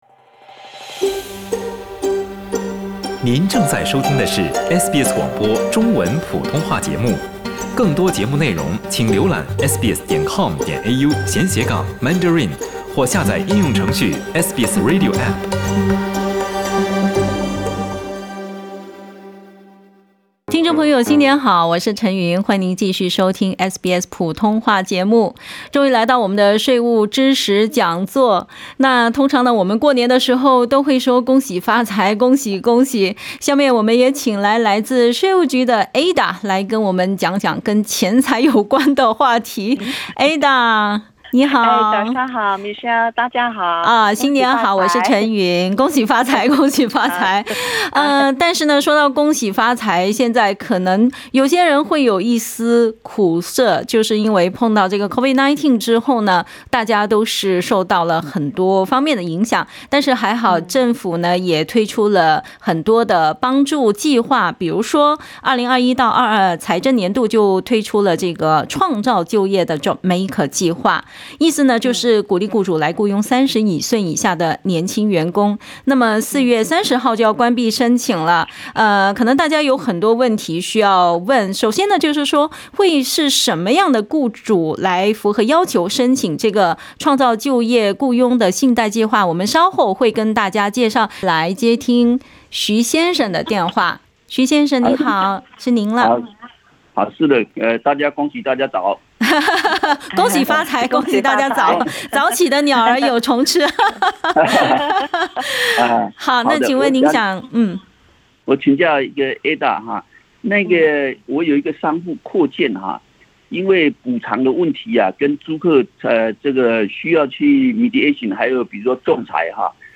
【税务知识讲座】退休后领福利金还要报税吗？